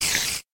Звуки пауков
Все звуки оригинальные и взяты прямиком из игры.
Шипение/Получение урона №2
SpiderIdle2.mp3